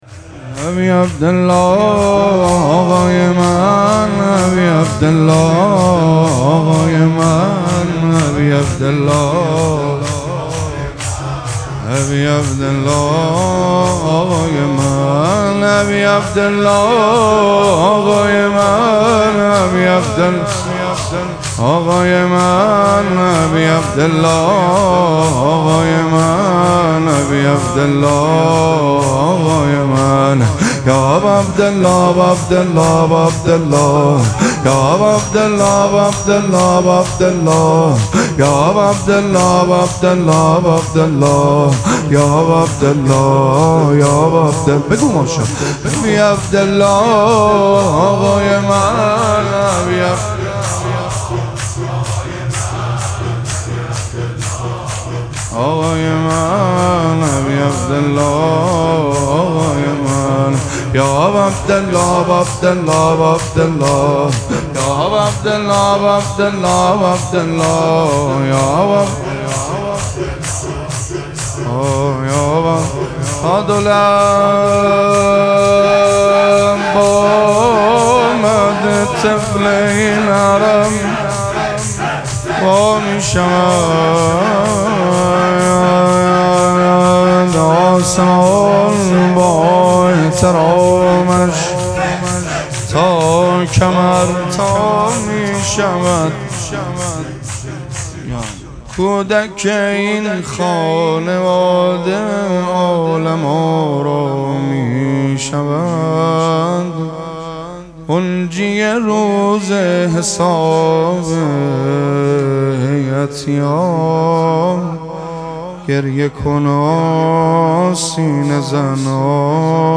مراسم مناجات شب دوازدهم ماه مبارک رمضان
حسینیه ریحانه الحسین سلام الله علیها
شور